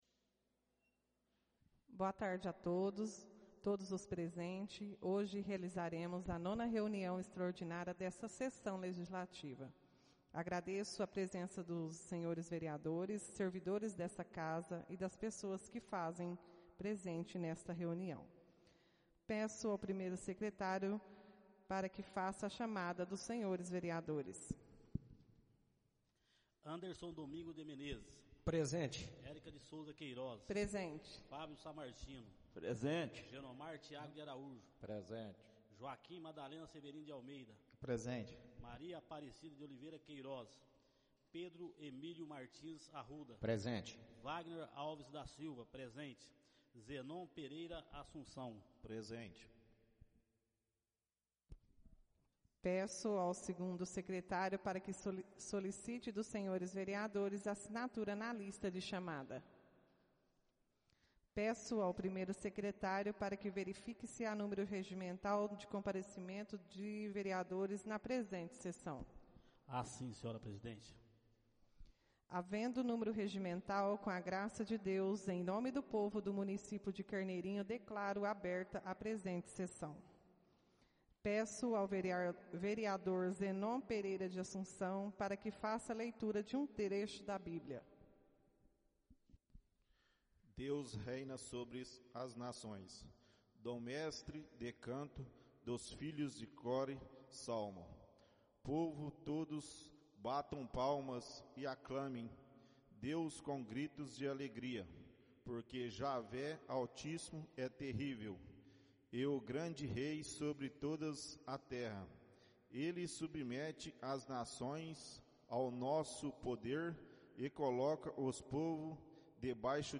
Áudio da 09.ª reunião extraordinária de 2022, realizada no dia 29 de Agosto de 2022, na sala de sessões da Câmara Municipal de Carneirinho, Estado de Minas Gerais.